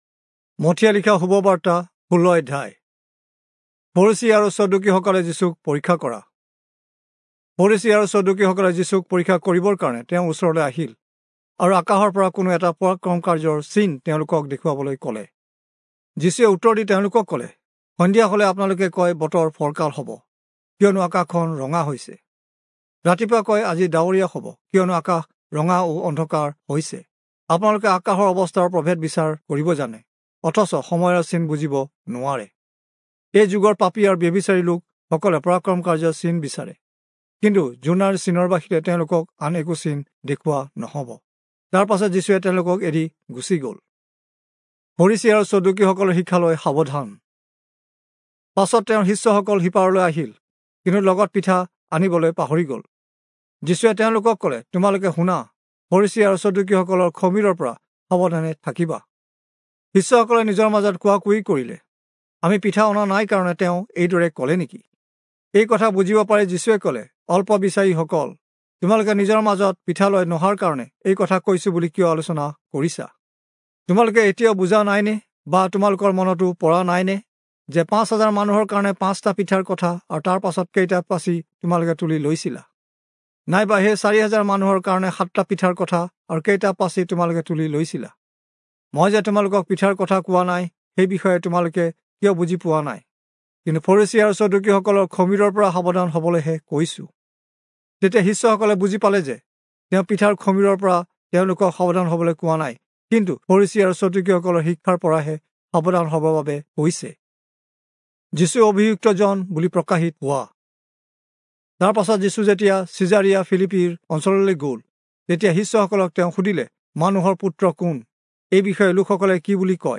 Assamese Audio Bible - Matthew 24 in Irvgu bible version